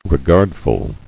[re·gard·ful]